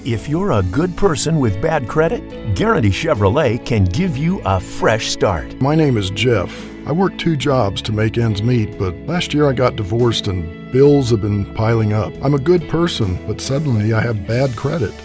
Male
a deep baritone voice with some grit and a tone of wisdom, authority, warmth and trust
Radio Commercials